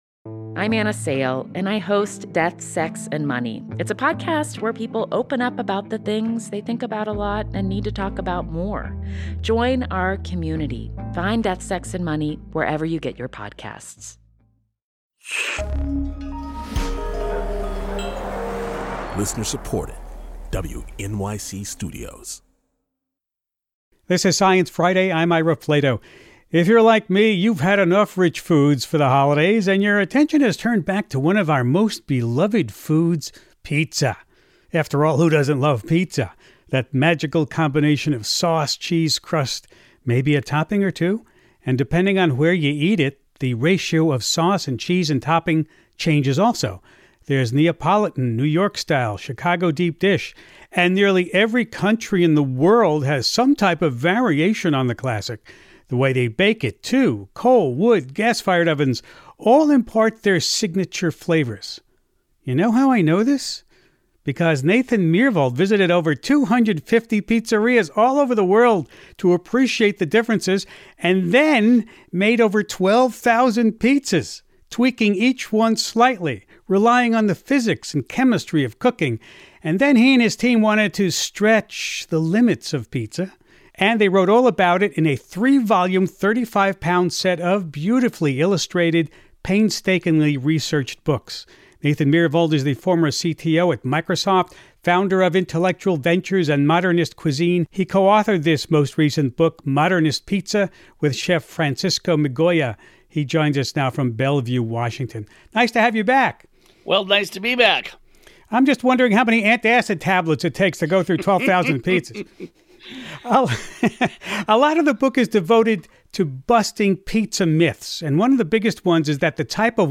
Ira talks with Nathan Myhrvold, former CTO at Microsoft, founder of Intellectual Ventures and Modernist Cuisine about his discoveries and his most recent book, Modernist Pizza.
In this short remembrance of Wilson, Ira replays selections from past conversations with the scientist, recorded between 2006 and 2013 .